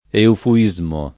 [ eufu- &@ mo ]